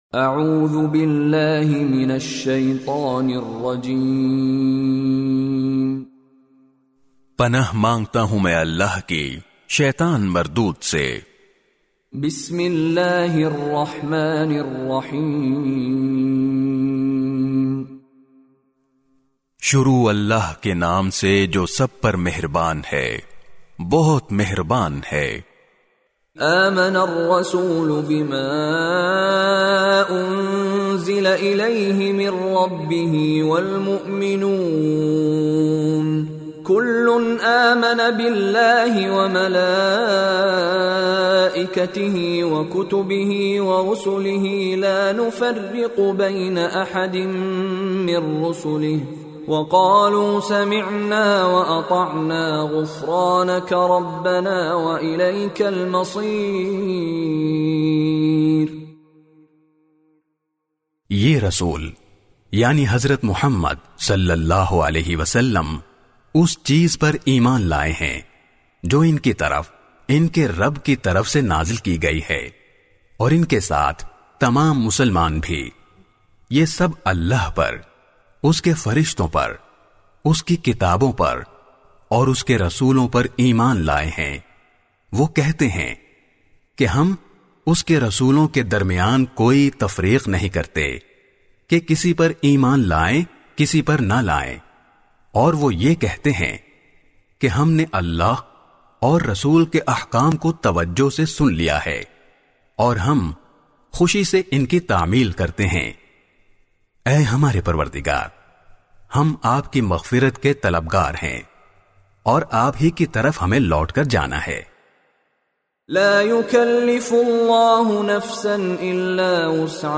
by Mishary Rashid Alafasy
CategoryTilawat
His melodious voice and impeccable tajweed are perfect for any student of Quran looking to learn the correct recitation of the holy book.